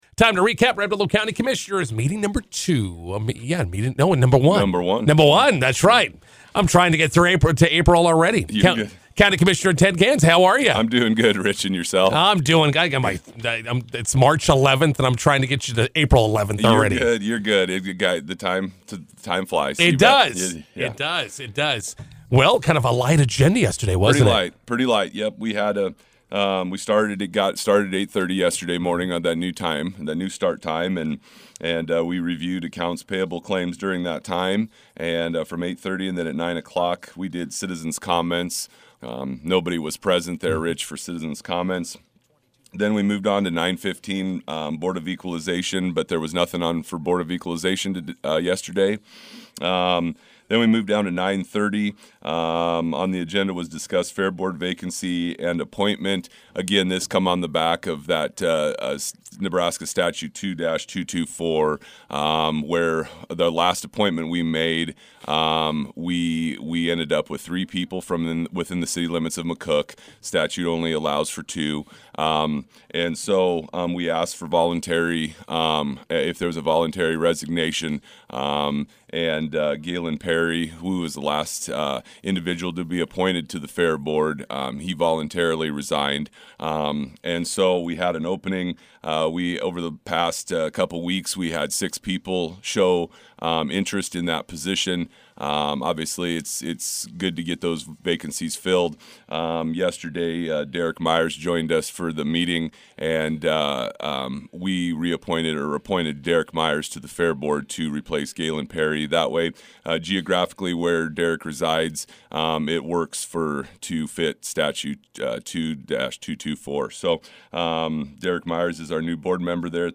INTERVIEW: Red Willow County Commissioners meeting recap with County Commissioner Ted Gans.